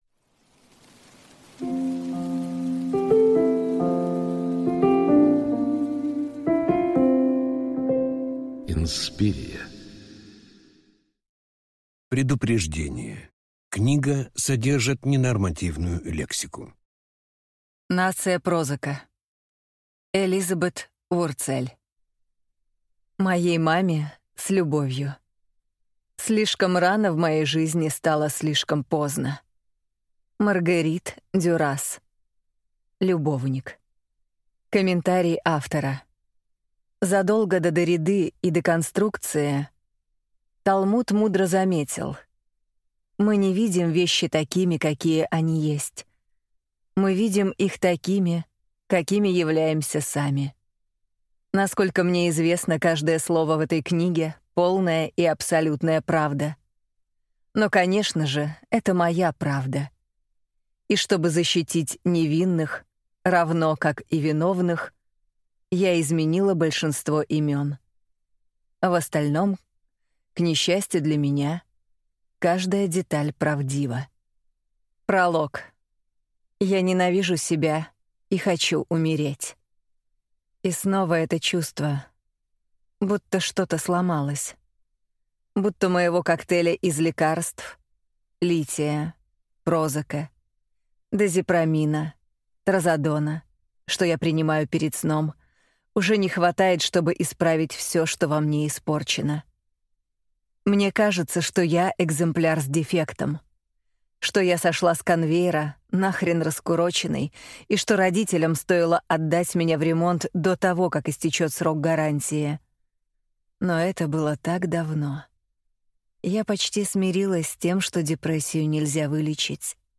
Аудиокнига Нация прозака | Библиотека аудиокниг
Прослушать и бесплатно скачать фрагмент аудиокниги